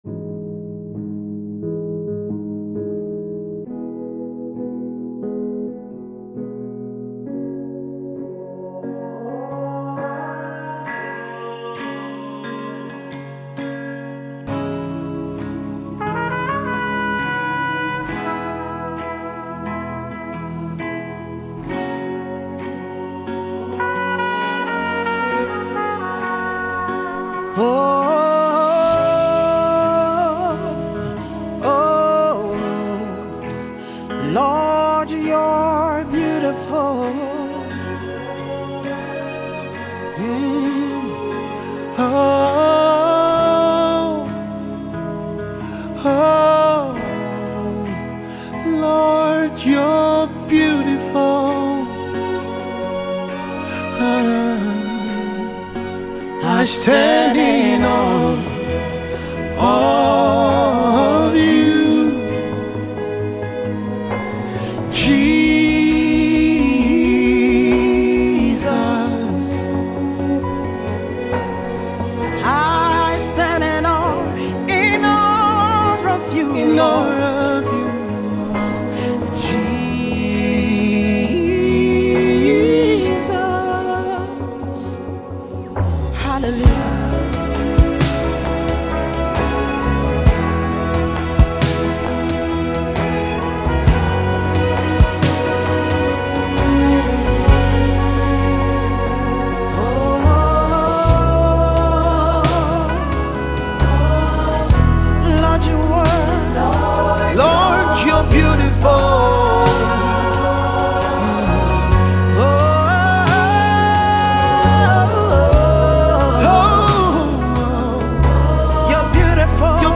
An Inspirational Gospel Singers